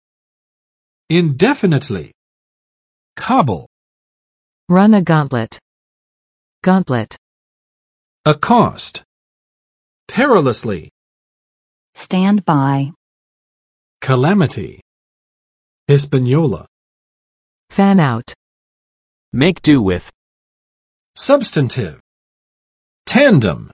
[ɪnˋdɛfənɪtlɪ] adv. 无限期地；不明确地，模糊地